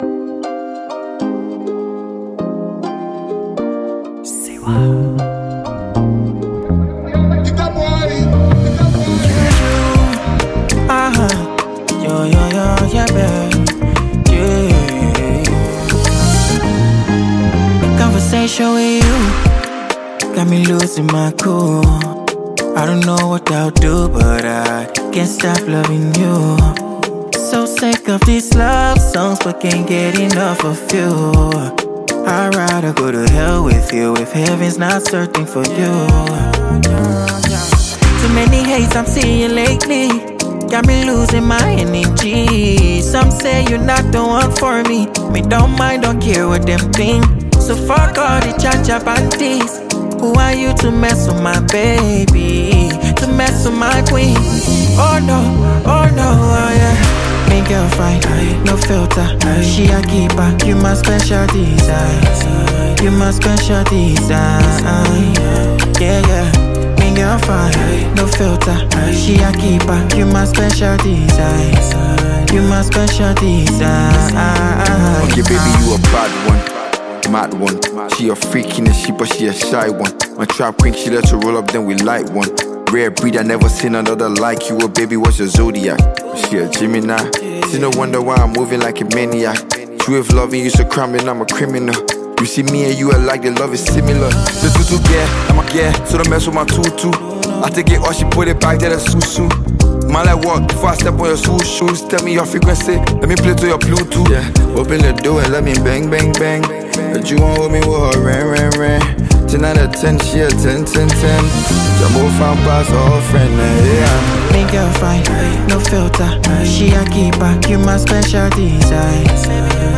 Categories: Afro-Beats,Afro-PopHip-CoHip-HopLiberian Music